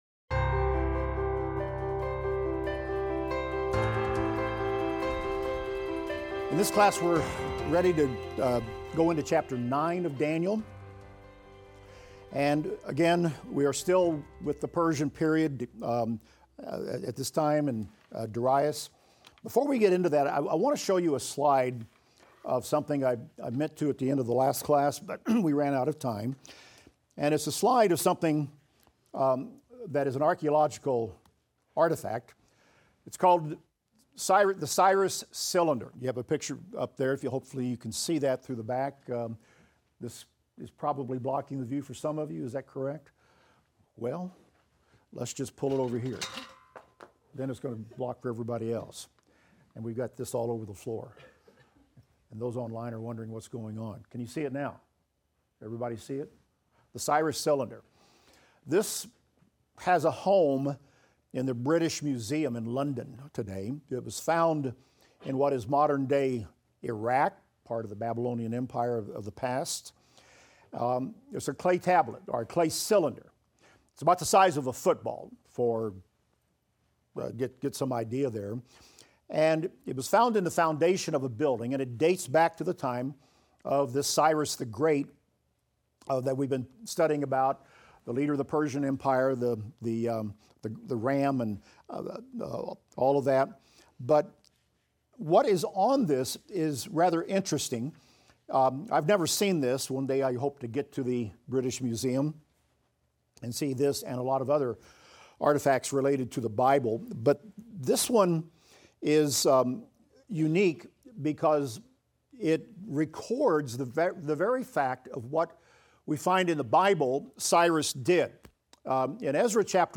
Daniel - Lecture 15 - audio.mp3